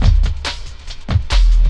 59 LOOP 01-R.wav